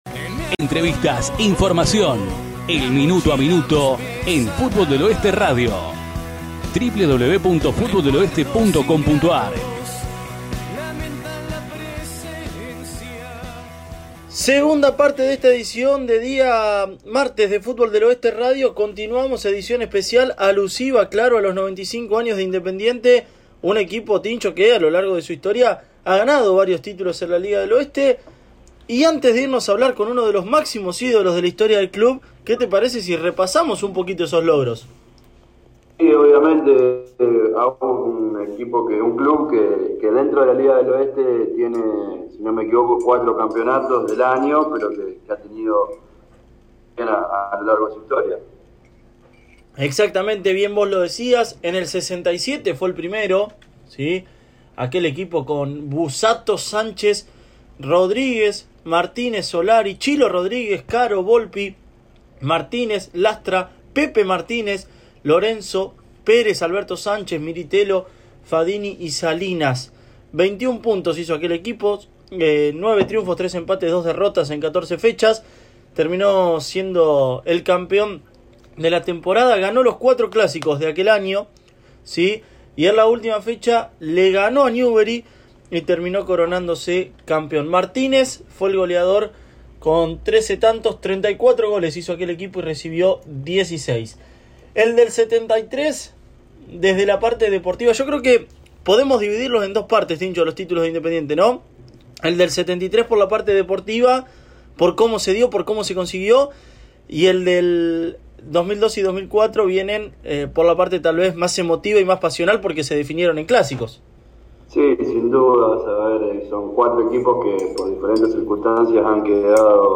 Nuestro programa radial tuvo su 49ª edición de este 2020, aquí te acercamos los 2 bloques para que puedas escucharlo a través de tu computadora o dispositivo móvil.